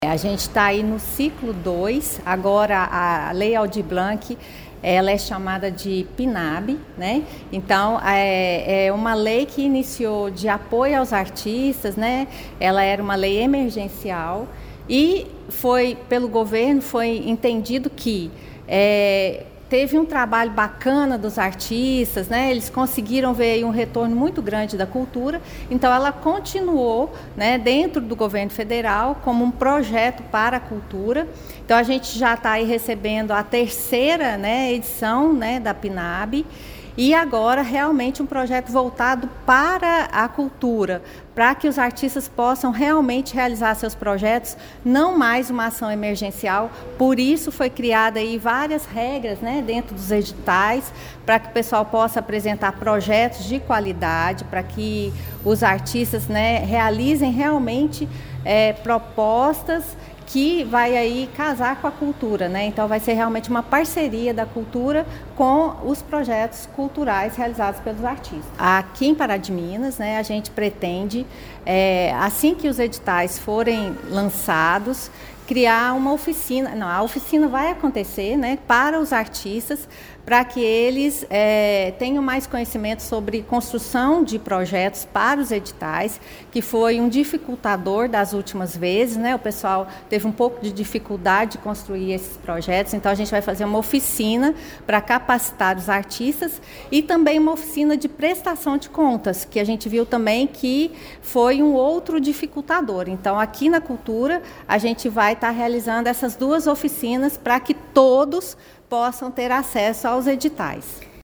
Entre as principais novidades, a secretária municipal de Cultura e Comunicação Institucional, Isabel Faria, afirma que o município implantará um grupo de estudos voltado à elaboração de projetos culturais, além de promover oficinas específicas de prestação de contas.